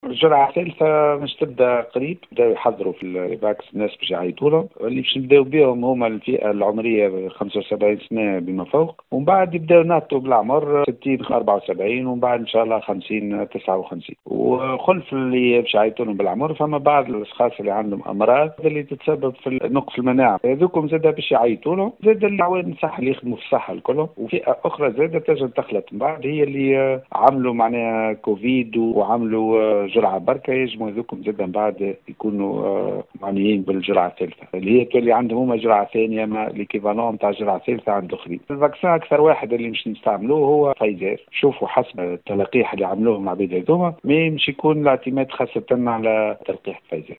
أفاد المدير العام للمركز العام الوطني لليقظة الدوائية ورئيس اللجنة العلمية للتلقيح رياض دغفوس، في تصريح ل “ام اف ام”، بأن العاملين بمنظومة إيفاكس شرعوا في إعداد قائمة الفئات المعنية بالجرعة الثالثة على أن يتم تقديمها قريبا.